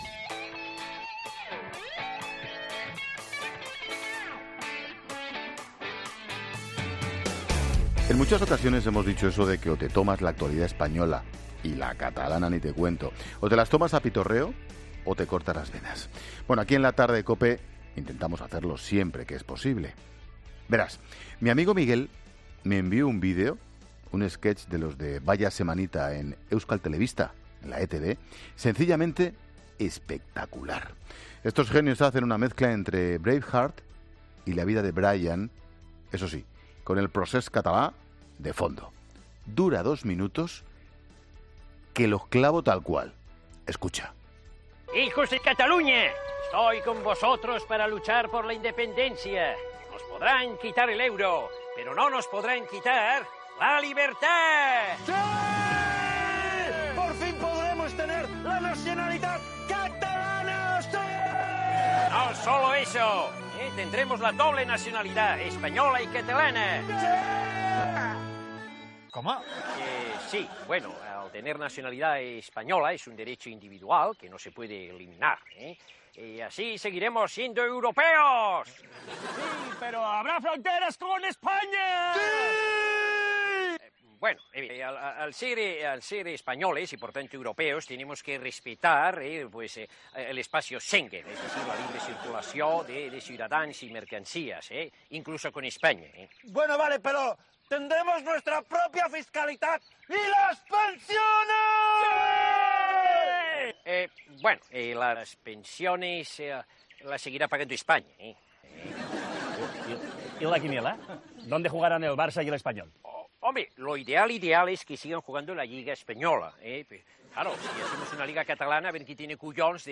Monólogo de Expósito
El monólogo de Ángel Expósito de las 16h a lo "Vaya Semanita".